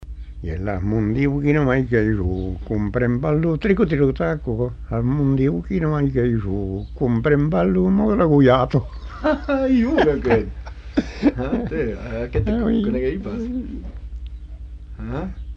Aire culturelle : Savès
Genre : chant
Effectif : 1
Type de voix : voix d'homme
Production du son : chanté
Danse : rondeau